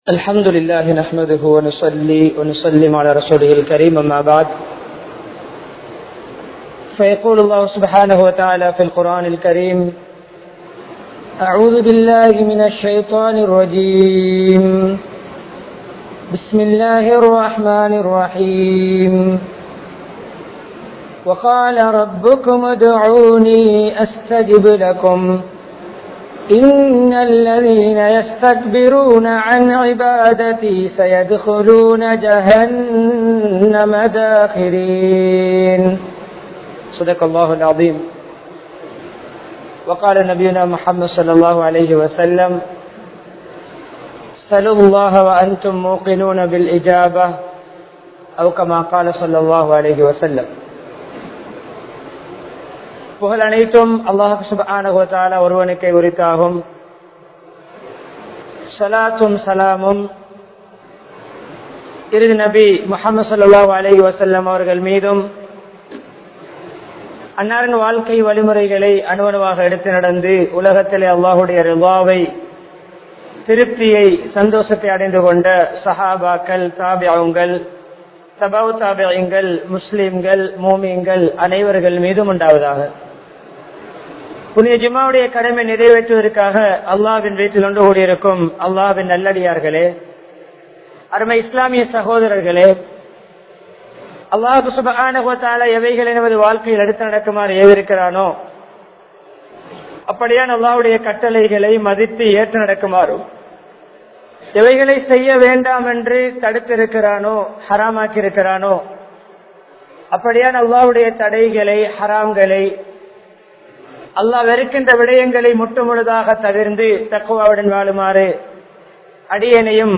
DUA vin Sakthi (துஆவின் சக்தி) | Audio Bayans | All Ceylon Muslim Youth Community | Addalaichenai